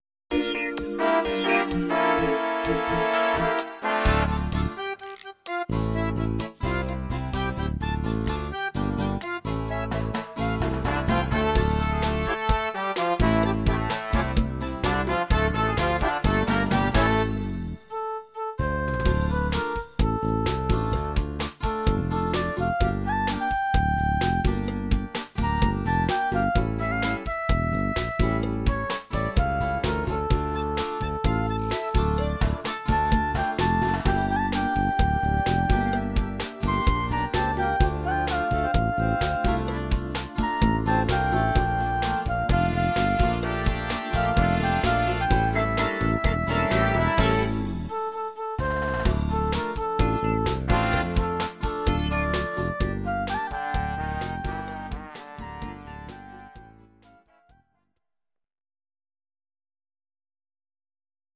Audio Recordings based on Midi-files
Ital/French/Span, 2000s